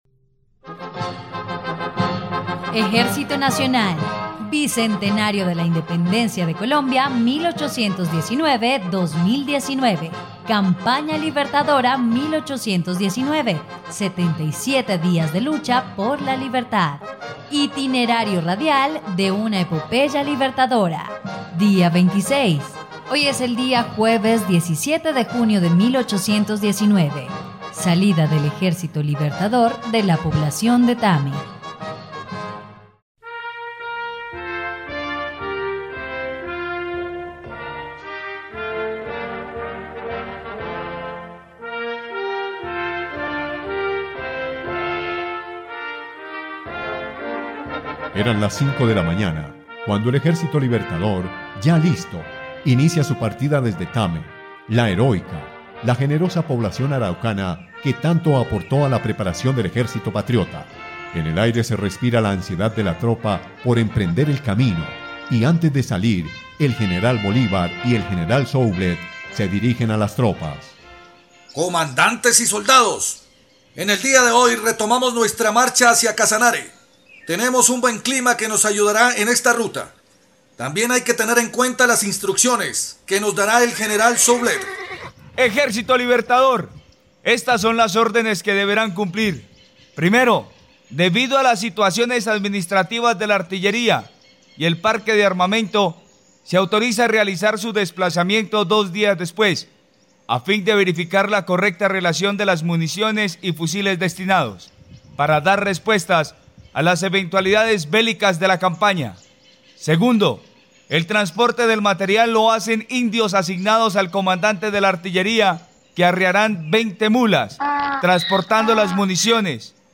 dia_26_radionovela_campana_libertadora.mp3